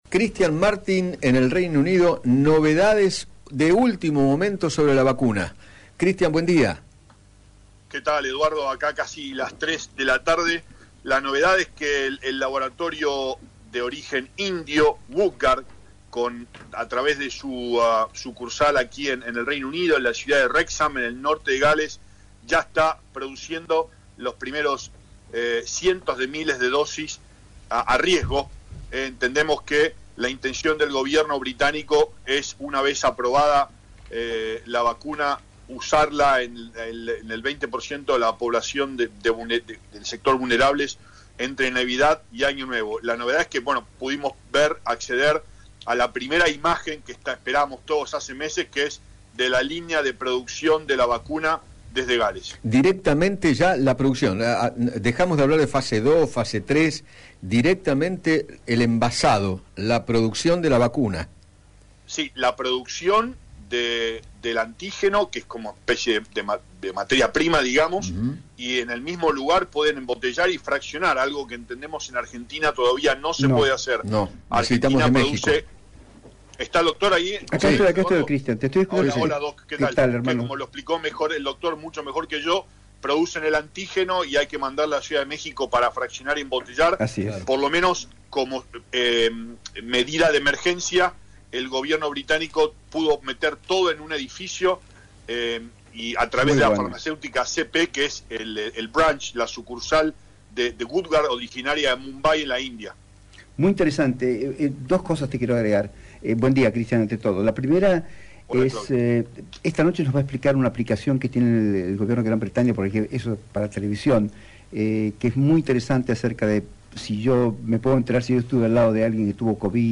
periodista argentino en Gran Bretaña, dialogó con Eduardo Feinmann sobre la producción de la vacuna de Oxford que está desarrollando el Laboratorio Serum y aseguró que “si bien es a riesgo